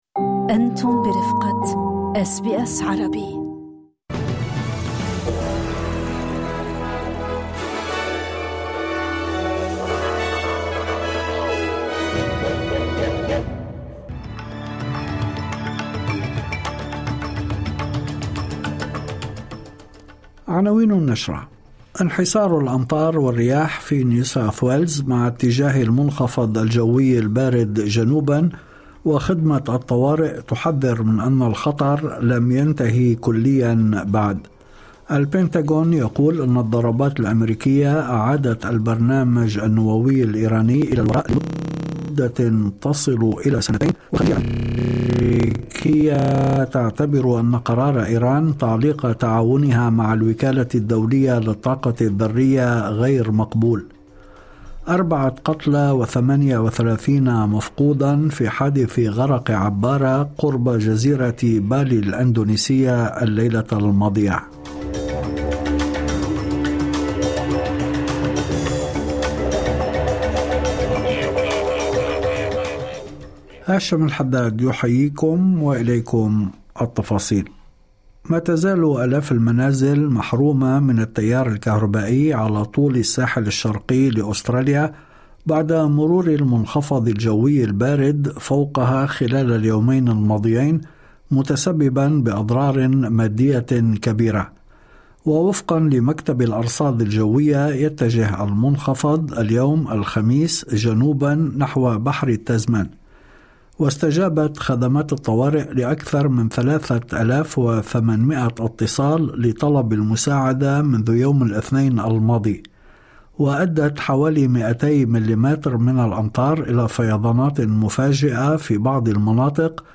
نشرة أخبار المساء 3/7/2025